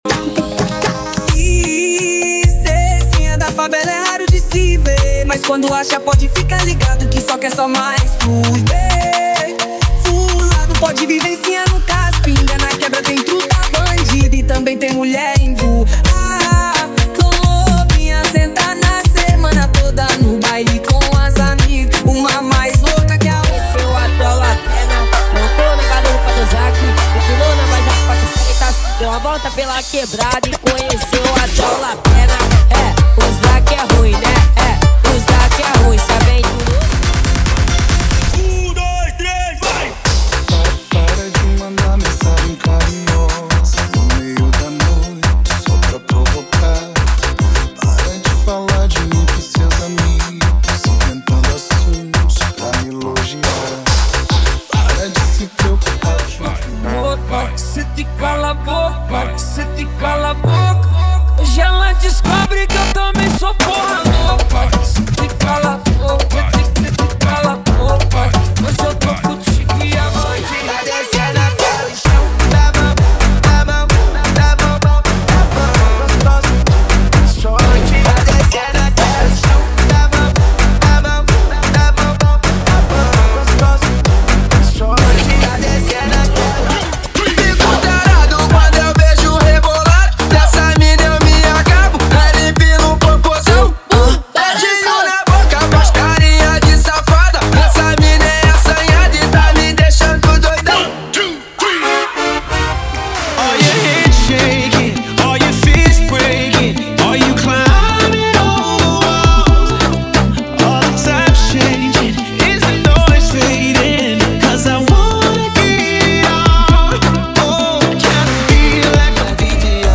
• Funk Light e Funk Remix = 136 Músicas
• Sem Vinhetas
• Em Alta Qualidade